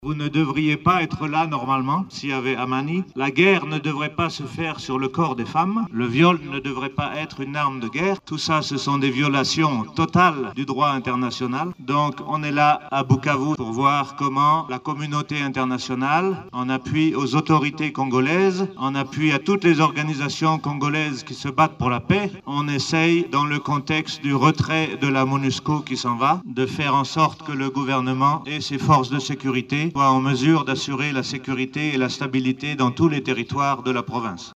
Ayant écouté les victimes dans leur plaidoyer, le coordonnateur résident du système de nations unies Bruno Lemarquis promet l’accompagnement du Groupe de Coordination des Partenaires afin que le viol ne soit plus utilisé comme arme de guerre.